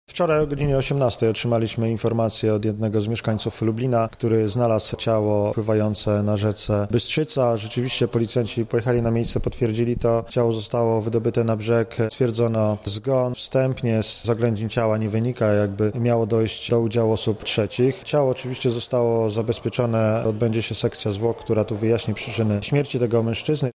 O tym jak przebiegała akcja i co udało się ustalić mówi podkomisarz